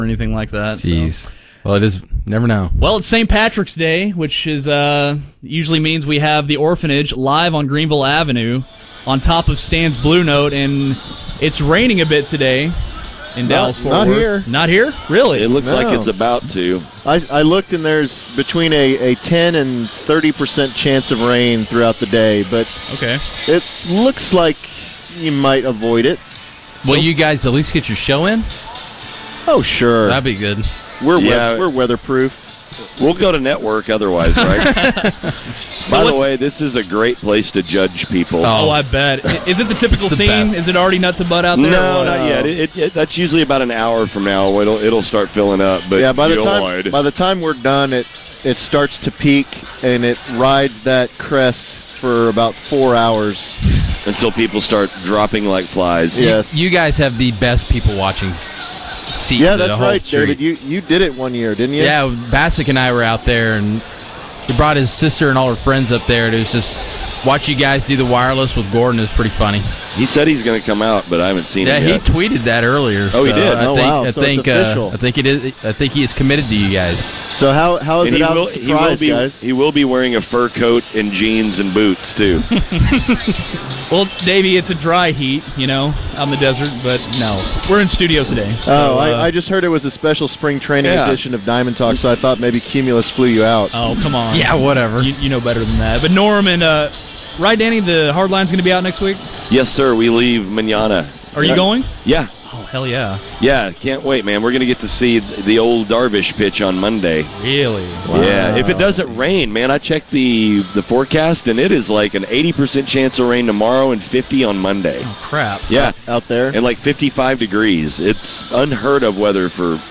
Every year after the St. Patrick’s Day parade, the Orphanage sets up on the roof of Stan’s and does their show.